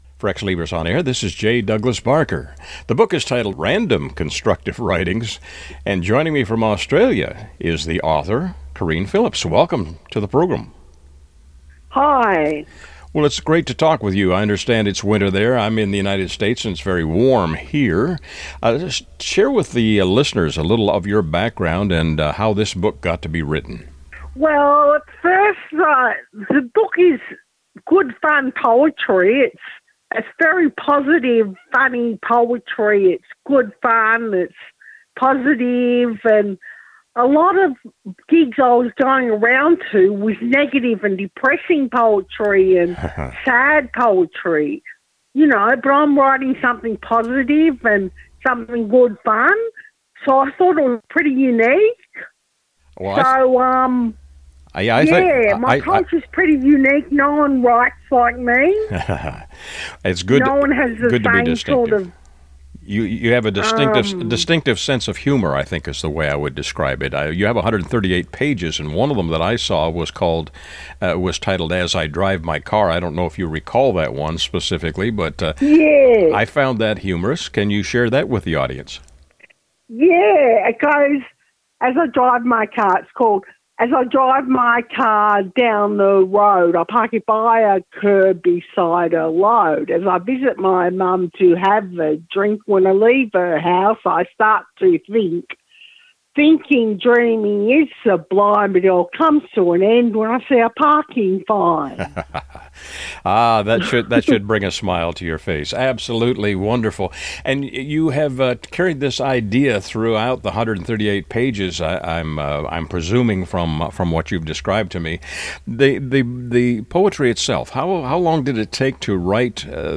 Radio Interview https